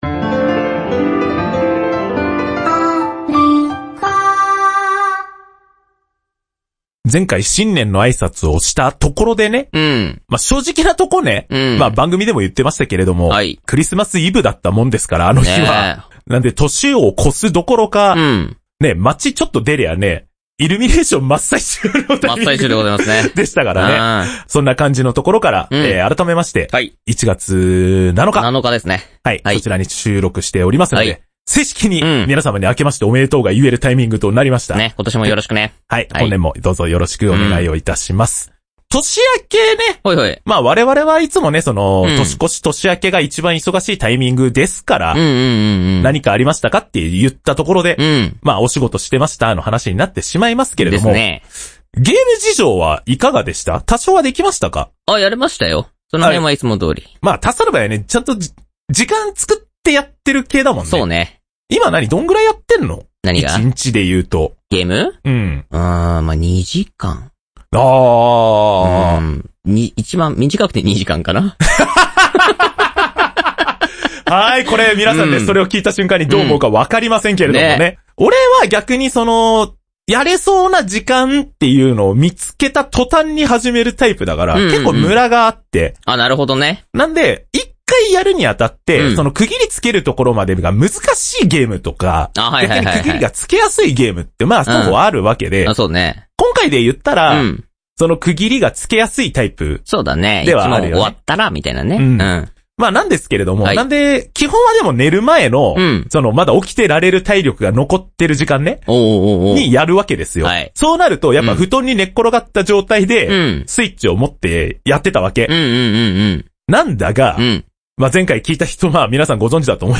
当時を懐かしみながら、他にも古今東西問わずリリースされたテレビゲームを、普及時・黎明期を共に触れてきた２人がレポートしながらゲームの面白さをお伝えします。